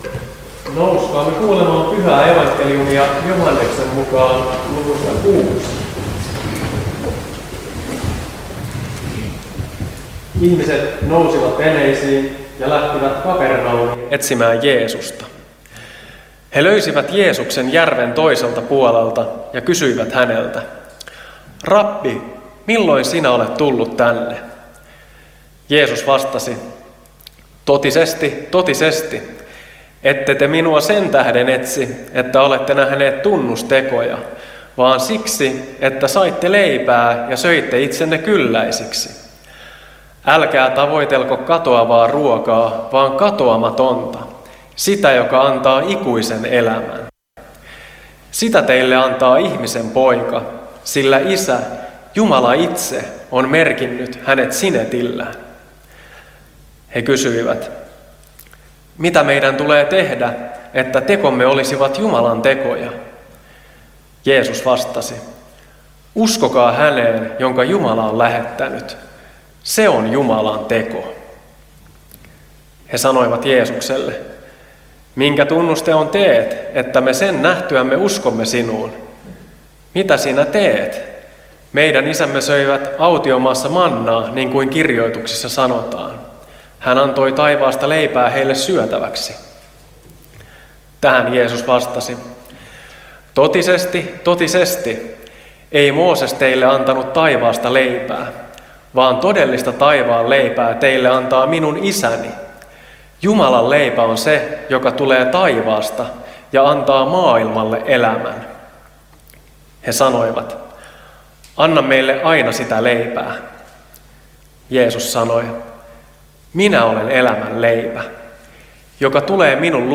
Lohtaja